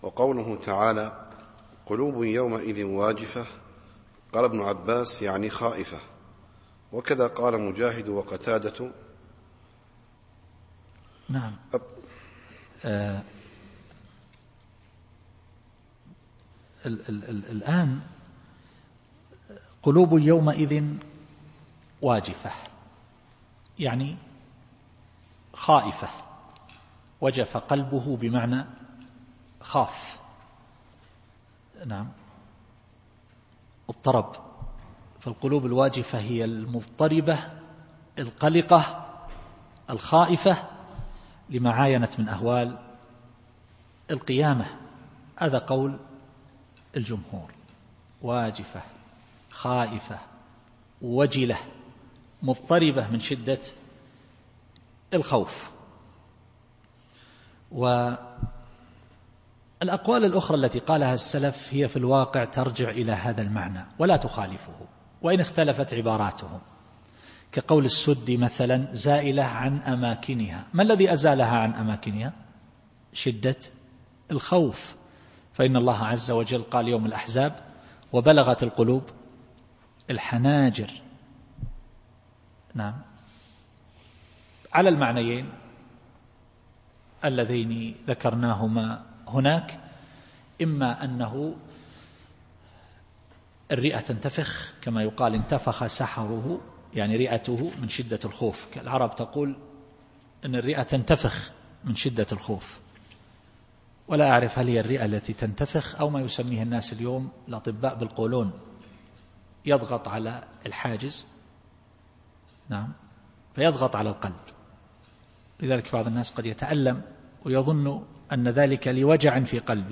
التفسير الصوتي [النازعات / 8]